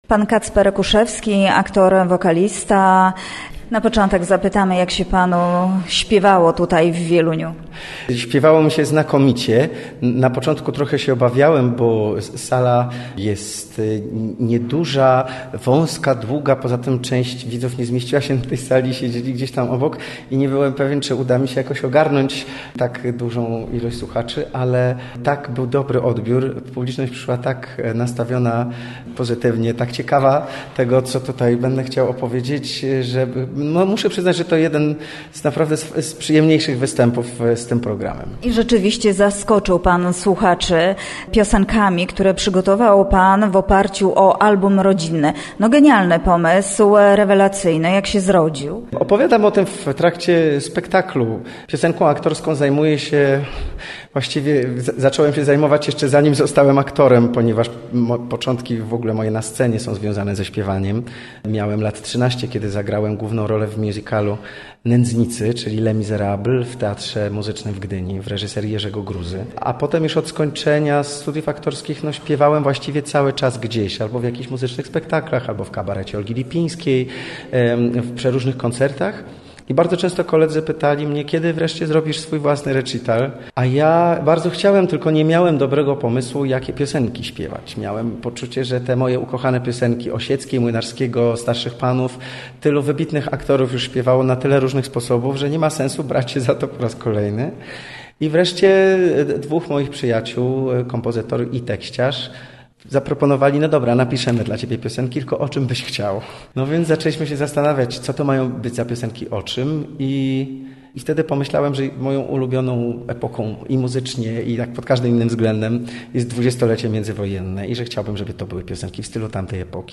Gościem Radia ZW był Kacper Kuszewski, aktor filmowy i wokalista
Nam w wywiadzie mówi o swojej fascynacji muzyką i różnorodnością sceny artystycznej, której może być częścią.